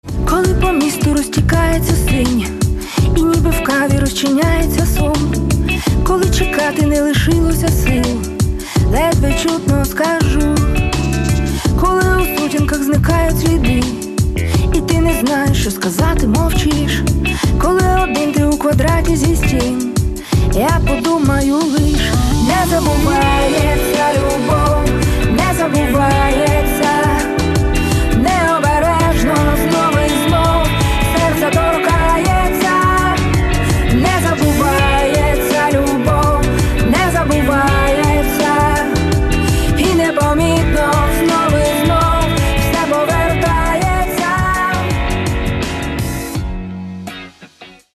Каталог -> Рок и альтернатива -> Поп рок